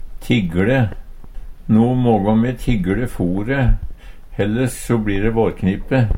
DIALEKTORD PÅ NORMERT NORSK tigLe spare Infinitiv Presens Preteritum Perfektum tigLe tigLa tigLa tigLa Eksempel på bruk No mågå me te tigLe fore, helles so bli de vårknipe.